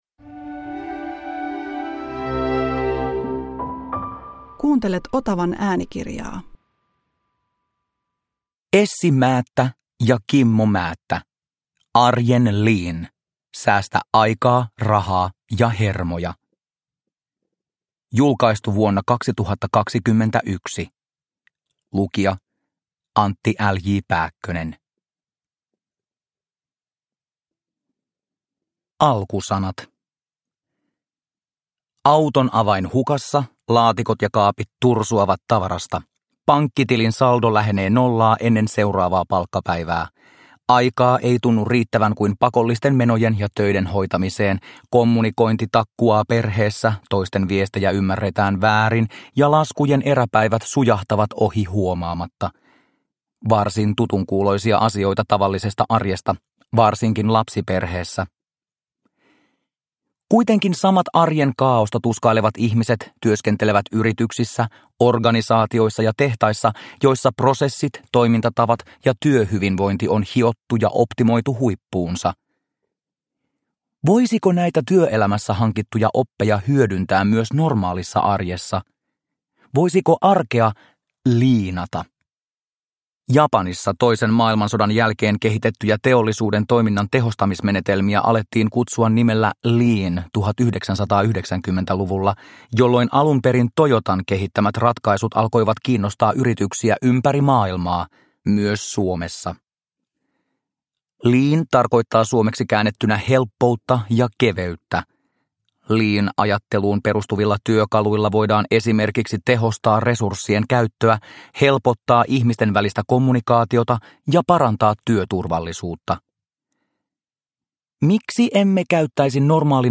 Arjen lean – Ljudbok – Laddas ner